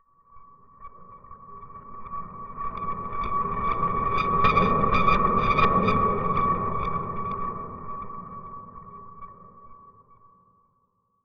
8 bits Elements
Terror Noises Demo
TerrorNoise_17.wav